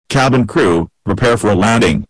cpt_landing.wav